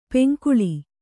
♪ peŋkuḷi